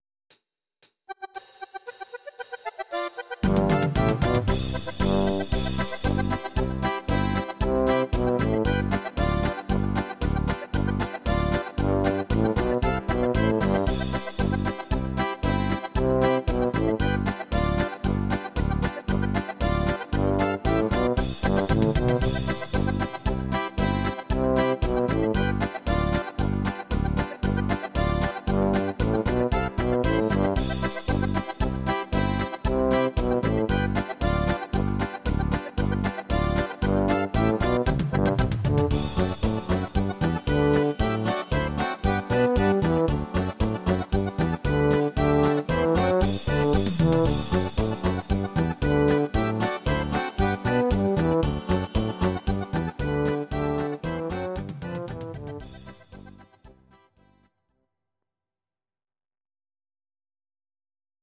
Audio Recordings based on Midi-files
German, Instrumental, Traditional/Folk, Volkstï¿½mlich